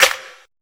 kits/Kanye/Snares/S (21).wav at 32ed3054e8f0d31248a29e788f53465e3ccbe498